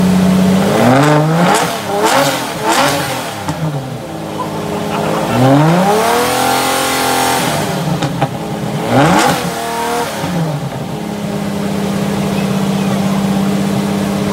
Download Lamborghini Diablo Sv sound effect for free.
Lamborghini Diablo Sv